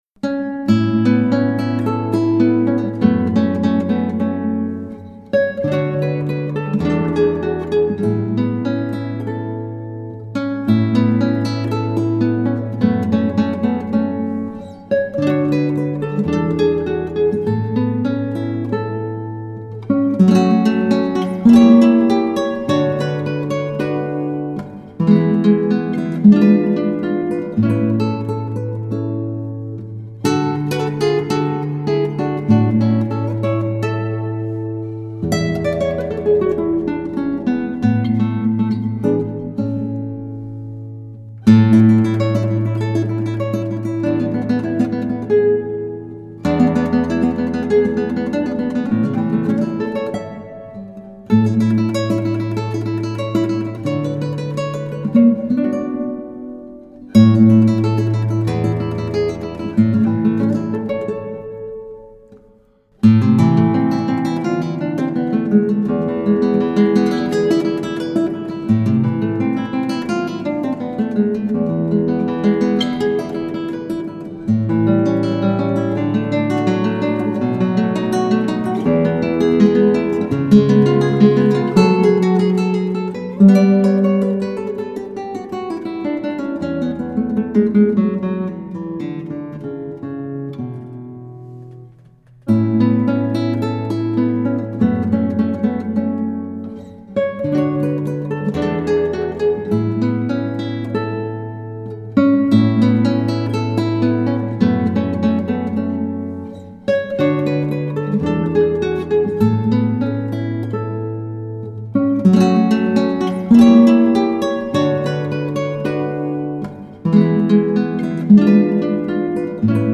Solo gitaar
• Thema: Klassiek